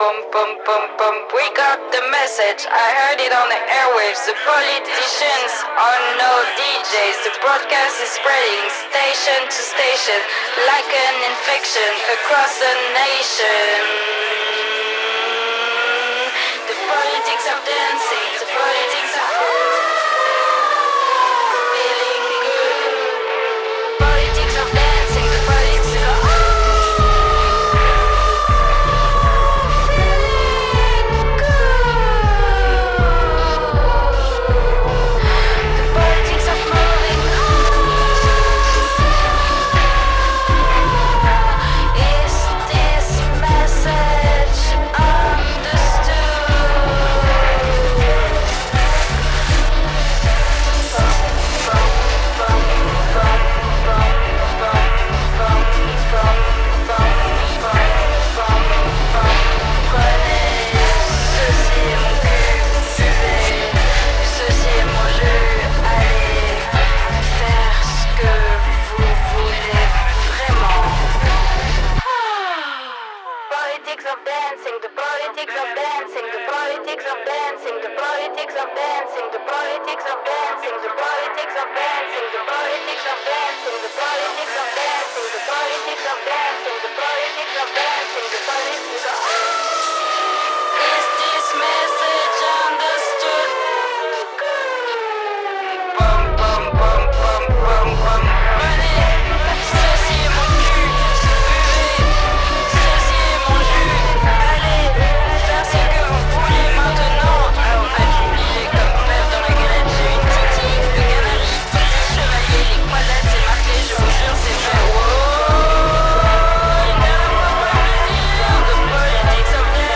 dans une cave..